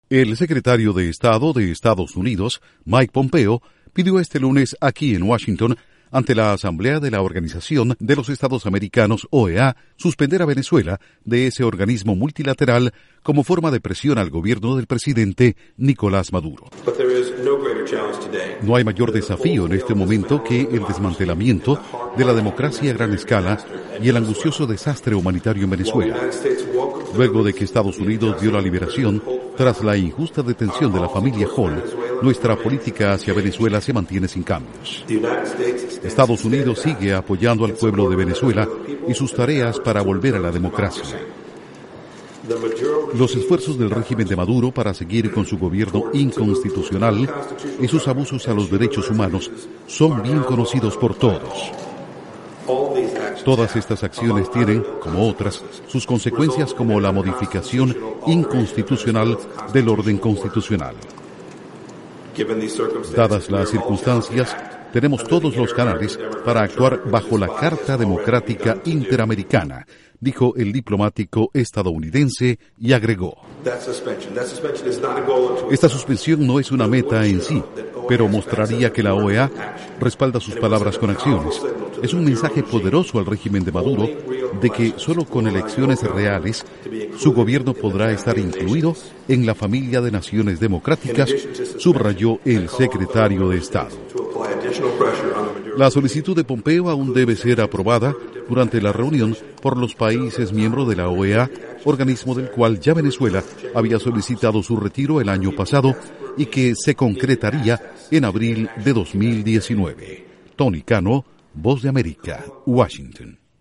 Duración: 1:53 3 audios de Mike Pompeo/ Secretario de Estado de EE.UU.